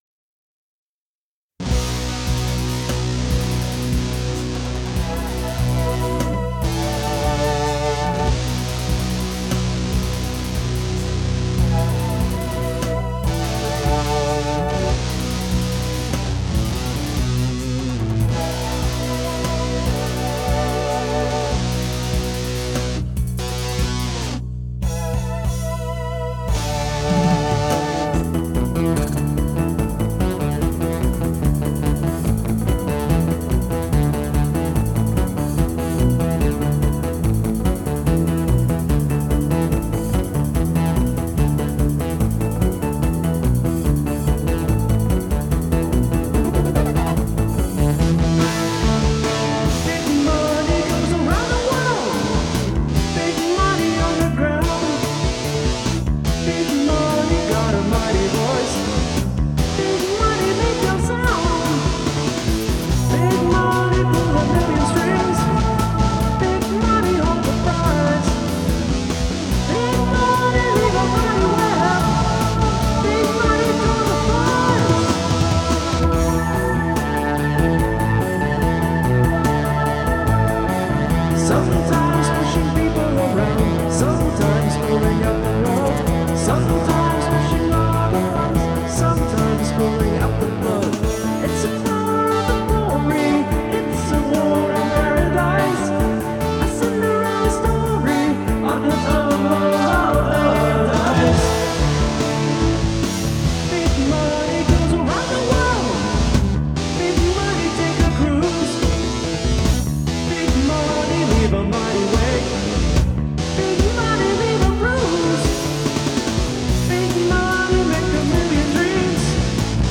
The guitar solo here is really unique.